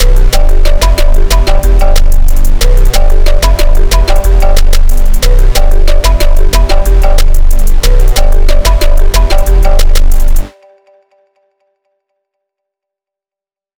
i think i just made the worst beat in history
Honestly, this would make a great alarm to get up in the morning.
this is what it sounds like to get woken up by your phone ringing
marimba.wav